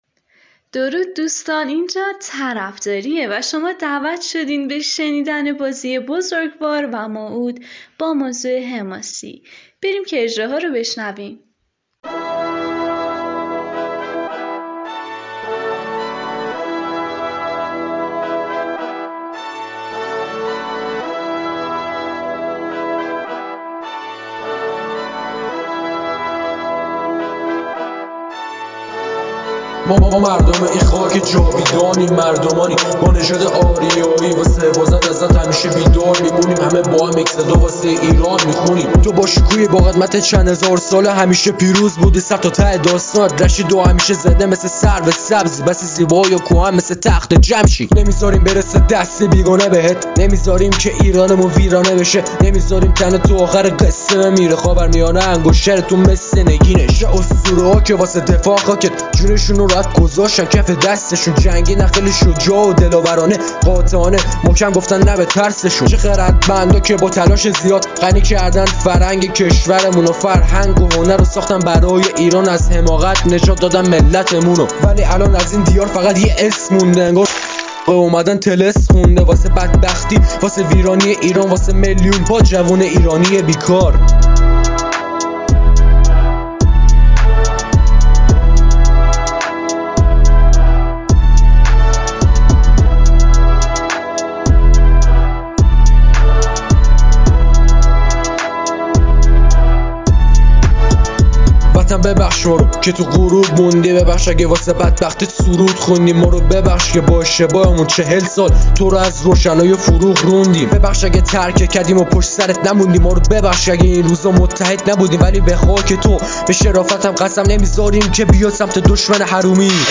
📝موضوع: حماسی
persian rap battle